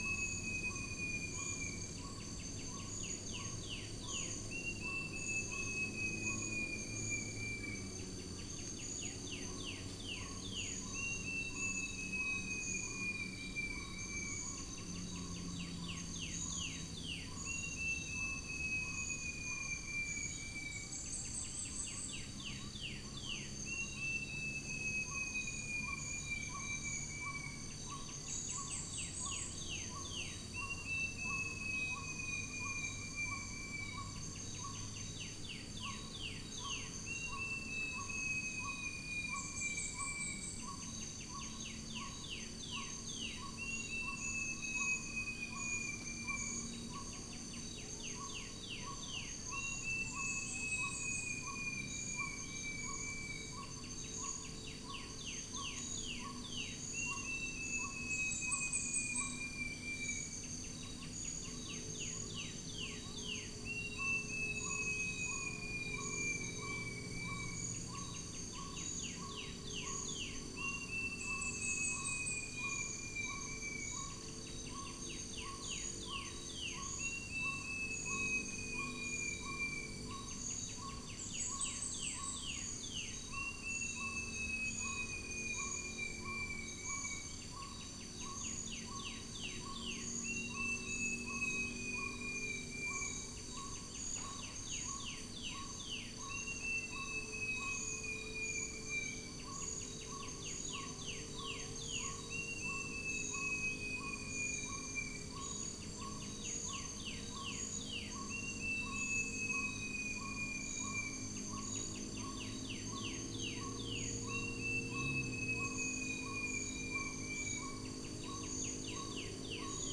Upland plots dry season 2013
Stachyris maculata
Trichastoma malaccense
Arachnothera hypogrammica